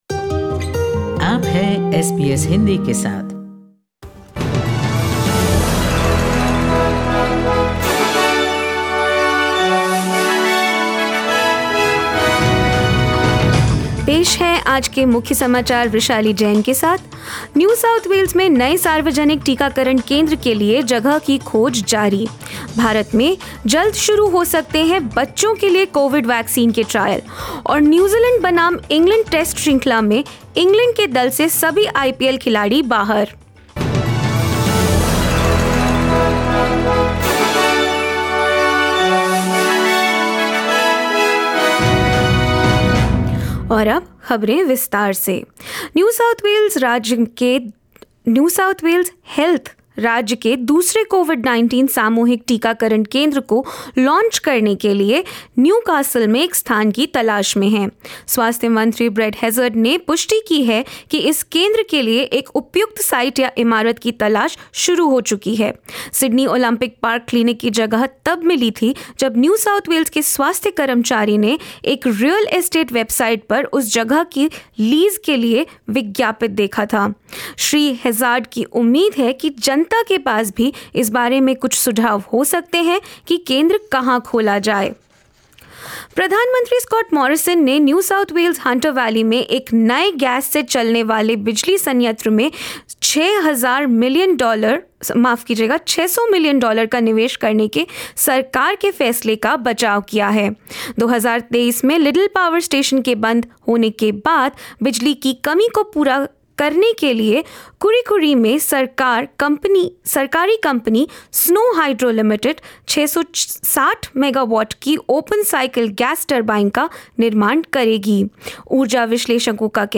In this latest SBS Hindi News bulletin of India and Australia: NSW to set up its second mass vaccination site in Newcastle; India to begin clinical trials of Covaxin coronavirus vaccine in children and more.